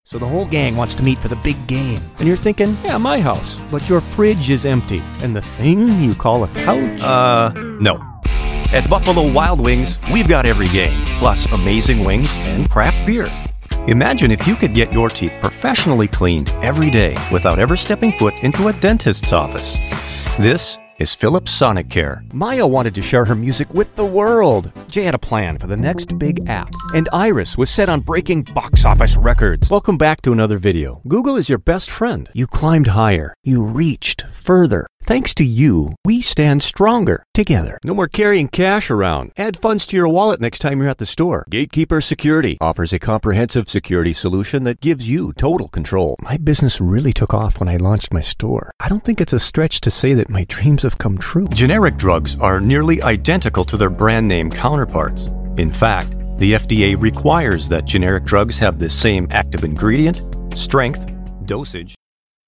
Professionally trained. Pro gear and studio.
Conversational - Personable - Guy Next Door style of voice. Believable and Friendly.
middle west
Sprechprobe: eLearning (Muttersprache):
Solid Work. Sharp Sound.